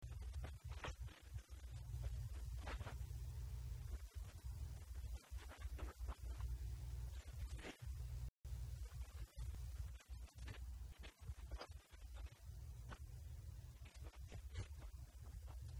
Elected reps then went round the table for a dialogue among peers, with Robert Campbell the sole voice wanting it to be by public ballot.